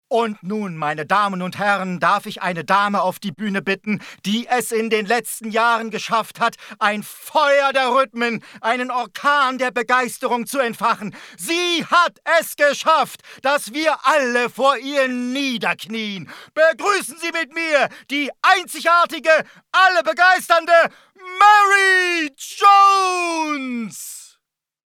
Ansager.mp3